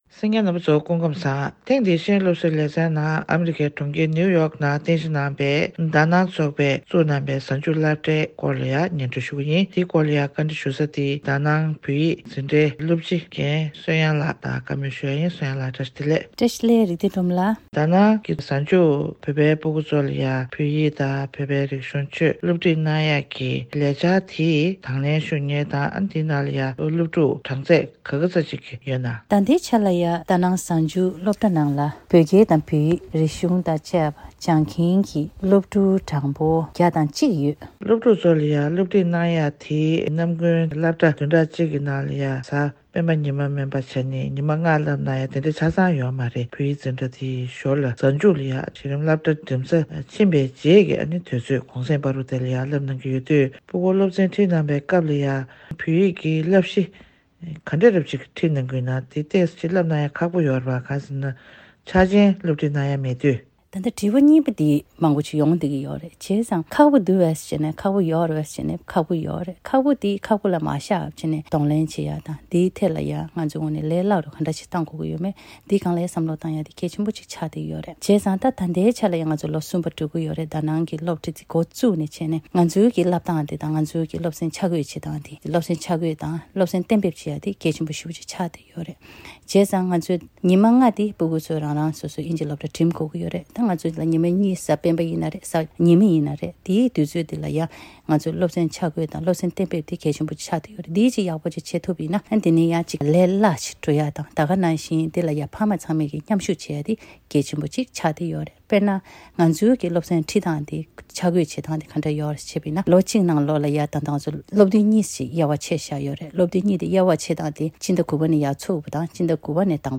བོད་པའི་སྐད་ཡིག་སློབ་འཁྲིད་དང་འབྲེལ་བའི་སྐོར་གནས་འདྲི་ཞུས་པ་ཞིག་གསན་རོགས་གནང་།